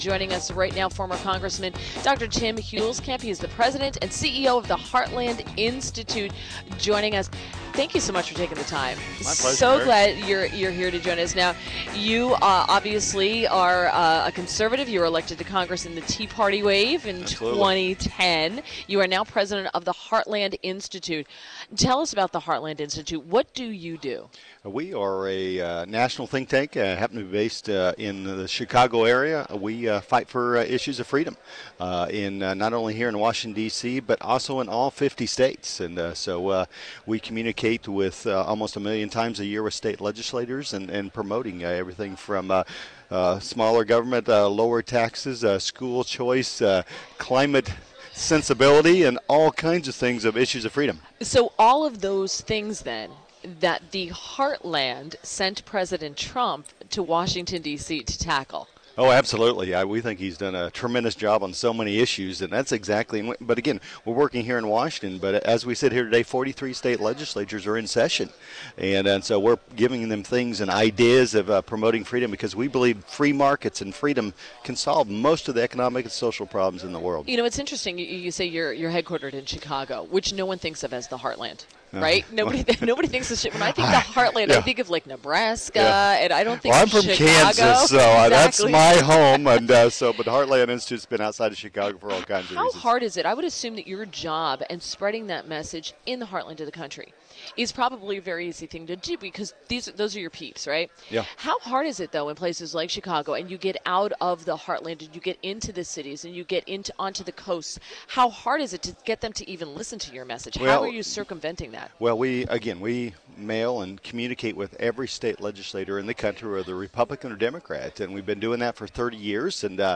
INTERVIEW - former Congressman DR. TIM HUELSKAMP - President and CEO of the Heartland Institute – discussed President Trump’s economic plan and government budget.